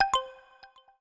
8 bits Elements
xilophone_5.wav